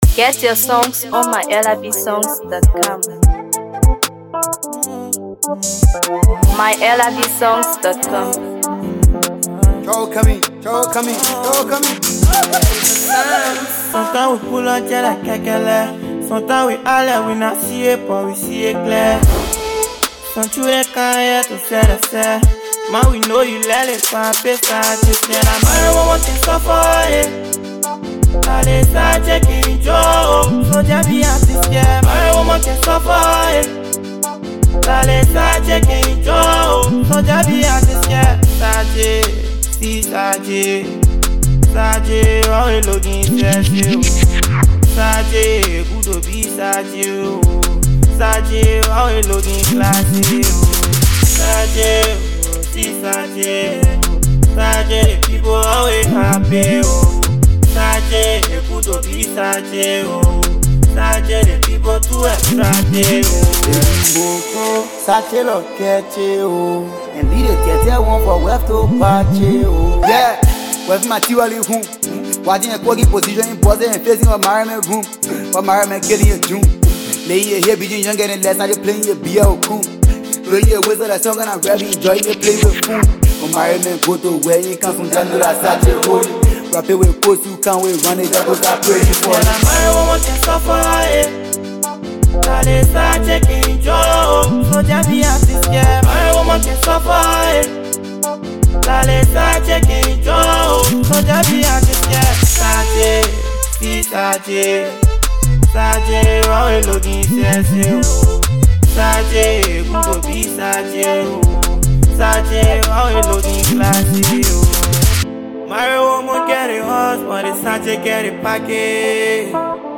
smooth, melodic Afrobeat vibes